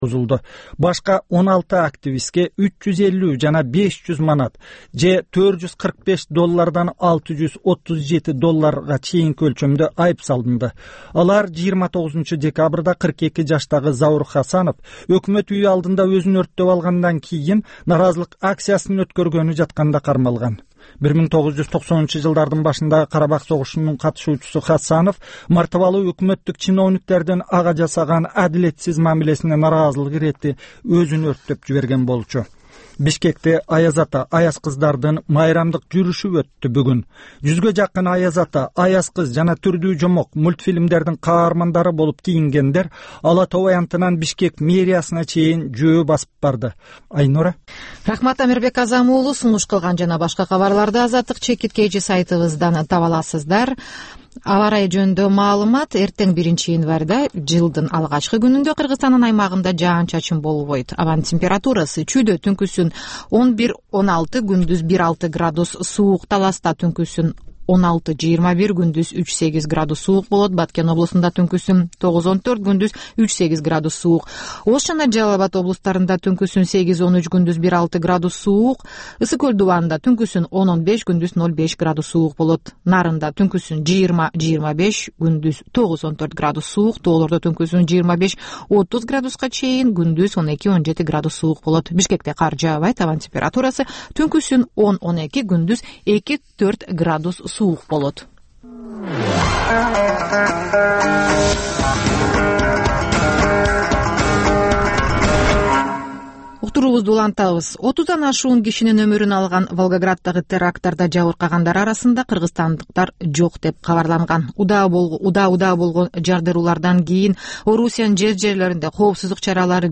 "Азаттык үналгысынын" бул кечки алгачкы жарым сааттык берүүсүнүн кайталоосу жергиликтүү жана эл аралык кабарлар, репортаж, маек, аналитикалык баян, сереп, угармандардын ой-пикирлери, окурмандардын э-кат аркылуу келген пикирлеринин жалпыламасы жана башка берүүлөрдөн турат.Ар күнү Бишкек убакыты боюнча саат 22:05тен 22:30га чейин кайталанат.